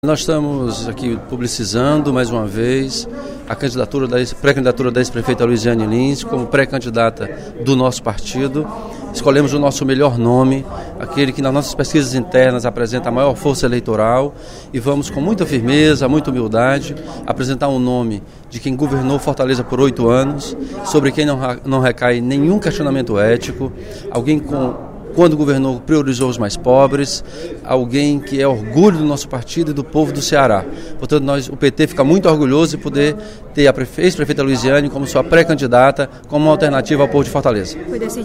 O deputado Elmano Freitas (PT) anunciou, nesta quinta-feira (02/06), durante o primeiro expediente da sessão plenária, o nome da ex-prefeita e atual deputada federal Luizianne Lins (PT/CE) como pré-candidata à Prefeitura de Fortaleza em 2016.